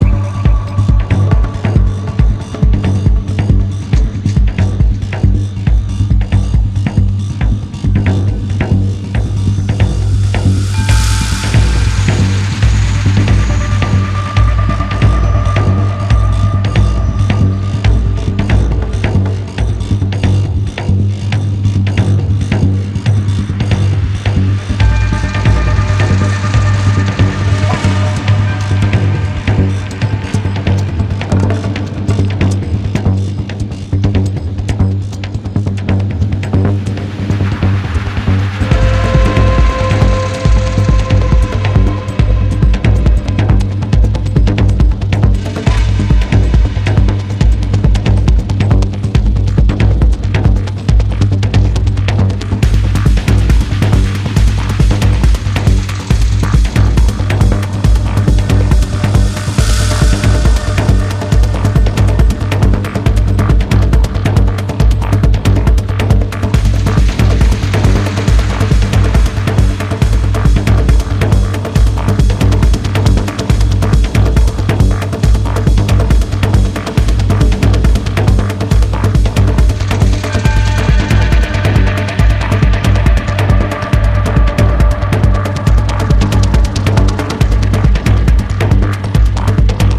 ポスト・トランス系にも通じるサイケデリックな音像が駆け巡る4/4疾走チューン